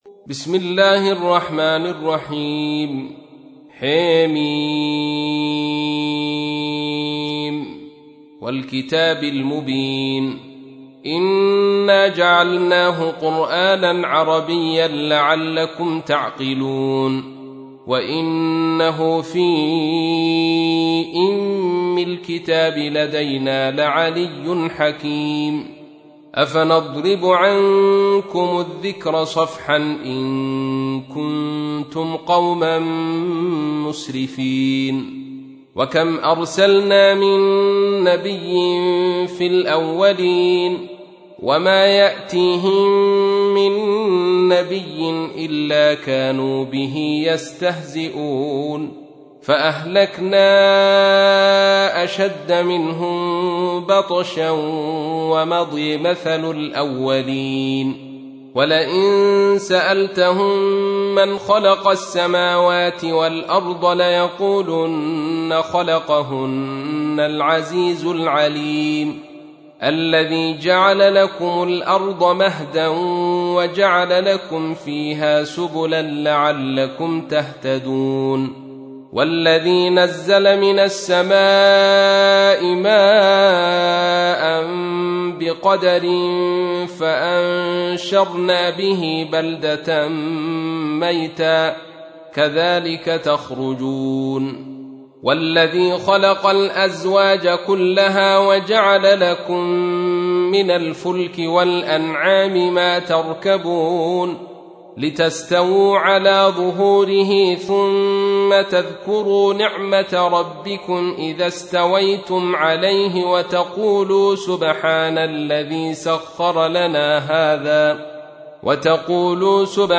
تحميل : 43. سورة الزخرف / القارئ عبد الرشيد صوفي / القرآن الكريم / موقع يا حسين